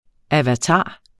avatar substantiv, fælleskøn Bøjning -en, -er, -erne Udtale [ avaˈtɑˀ ] eller [ ˈavatɑː ] Oprindelse fra sanskrit avatara , 'nedstigning' Betydninger 1.